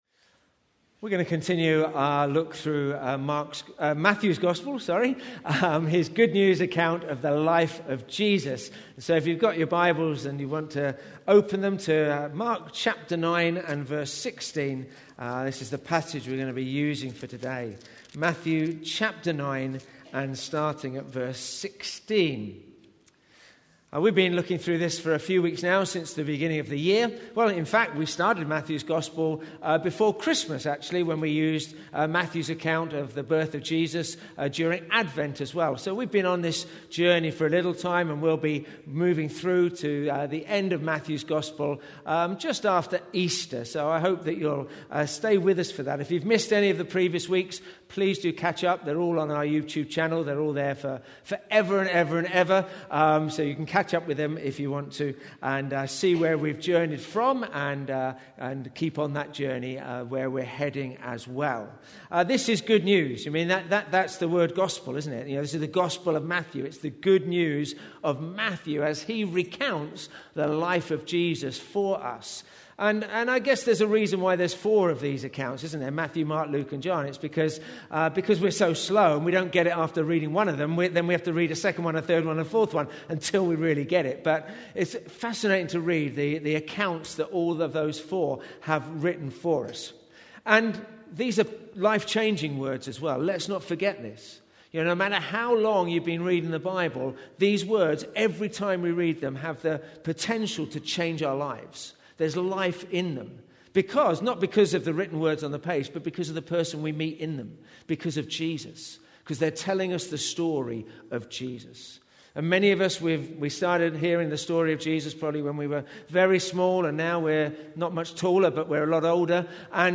Preacher
The Gospel of Matthew Watch Listen play pause mute unmute Download MP3 Thanks for joining us as we join together in worship both in-person and online. We're continuing our journey through Matthew's gospel this morning as we explore Jesus' teaching in Matthew 8 and 9.